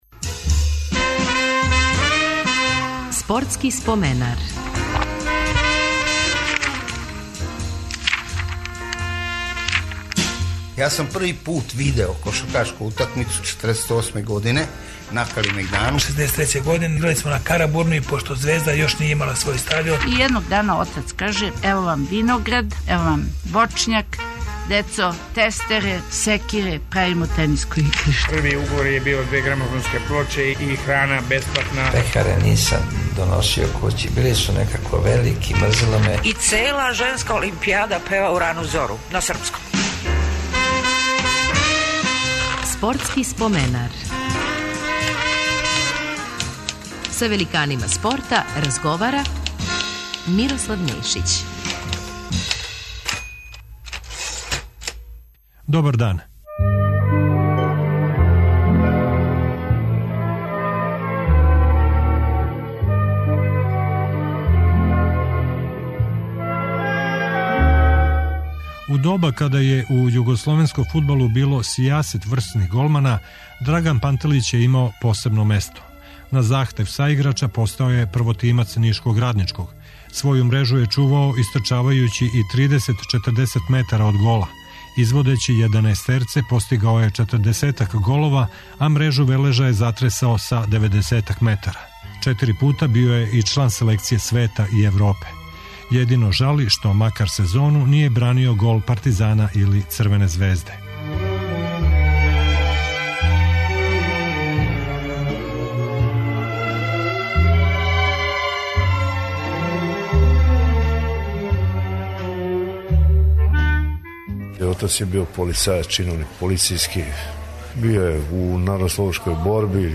Гост ће нам бити фудбалер Драган Пантелић.